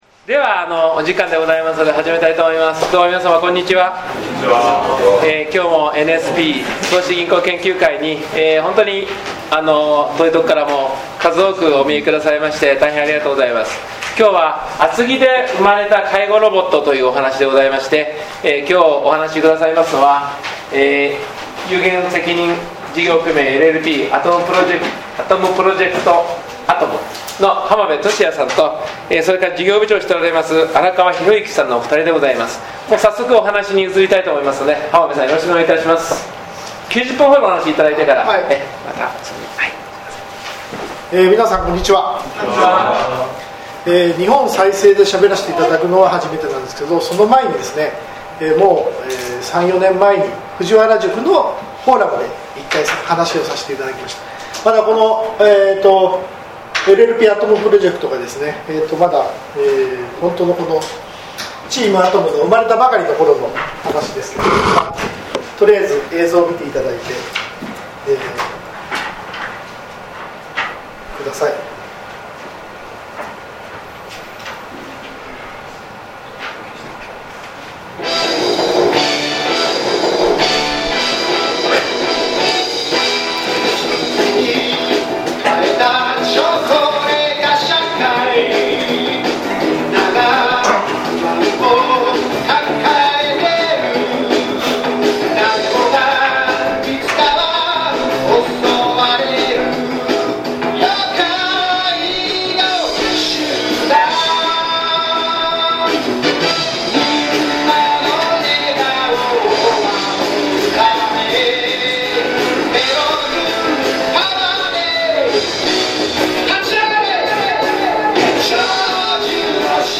「厚木で生まれた介護ロボット」音声・動画 第4回 NSP投資銀行研究会 | 認定NPO法人 日本再生プログラム推進フォーラム